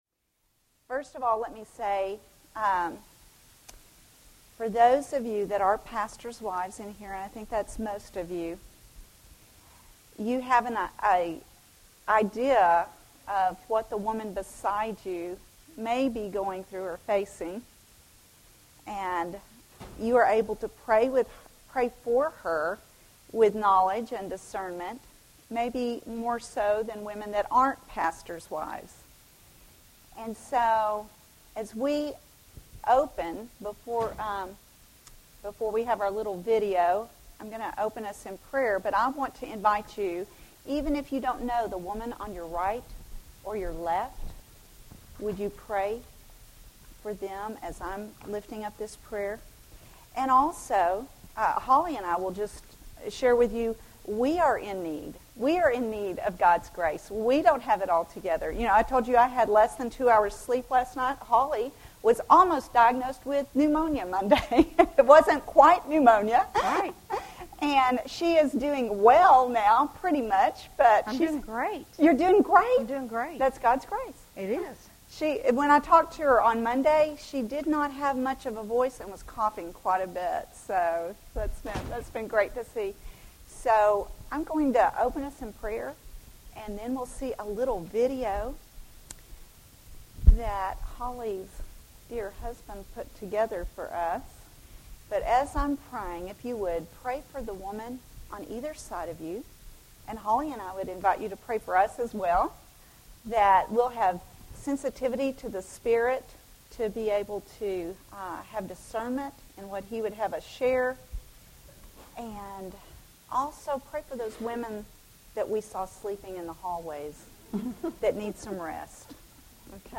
Join them for a conversation centered around encouragement and practical help from the Word.